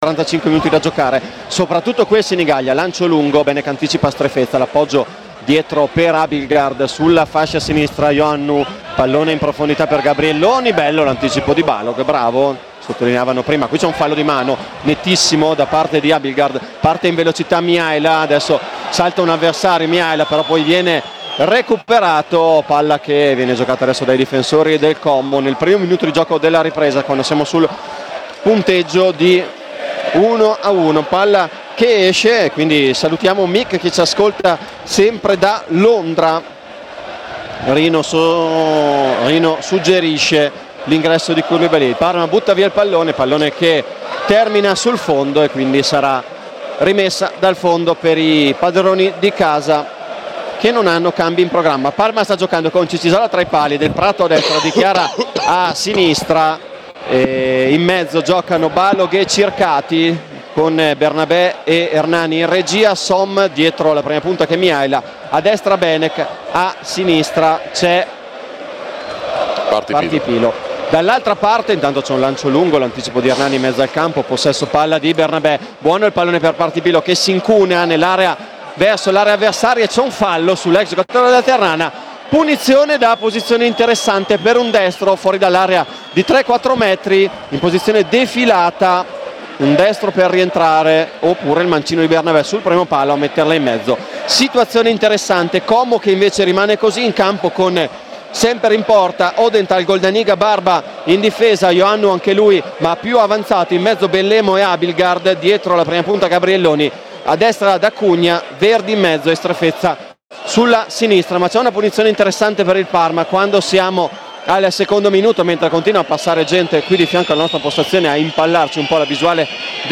Radiocronaca
Commento tecnico